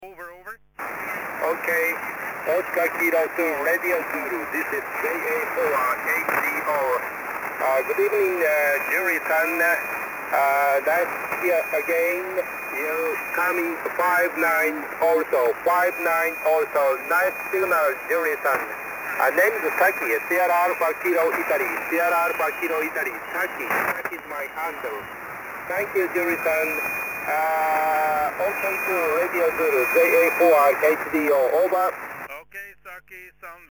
Jak posloucháte DXy v pásmu 80m?